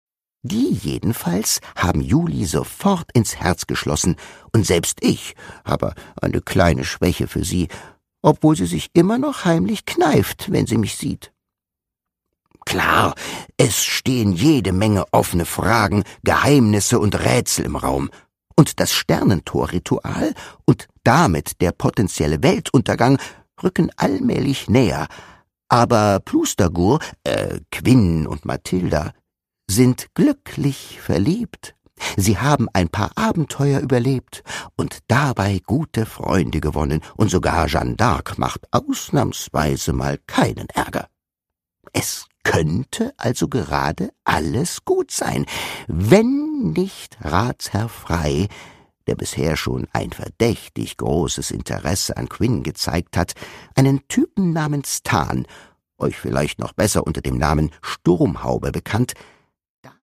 Produkttyp: Hörbuch-Download
Gelesen von: Timmo Niesner, Jasna Fritzi Bauer